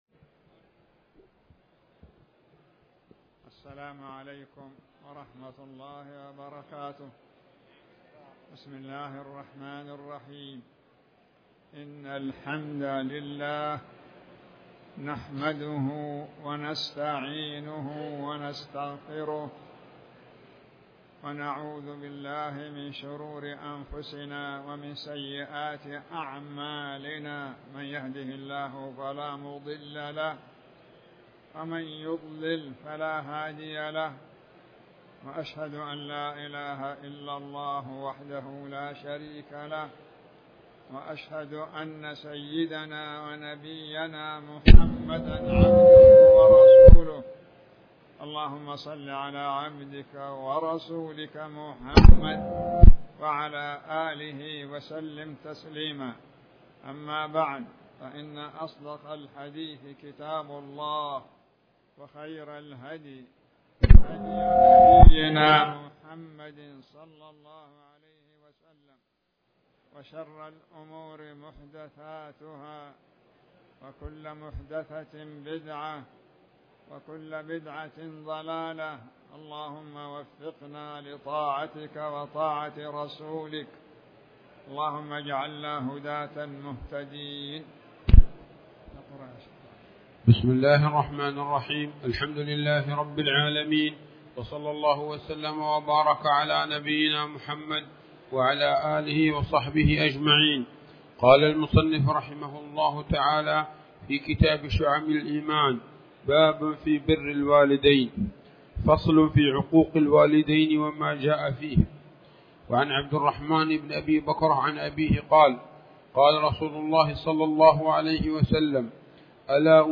تاريخ النشر ١٥ محرم ١٤٤٠ هـ المكان: المسجد الحرام الشيخ